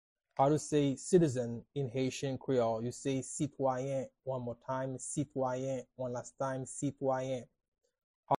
“Citizen” in Haitian Creole – “Sitwayen” pronunciation by a native Haitian tutor
“Sitwayen” Pronunciation in Haitian Creole by a native Haitian can be heard in the audio here or in the video below:
How-to-say-Citizen-in-Haitian-Creole-–-Sitwayen-pronunciation-by-a-native-Haitian-tutor.mp3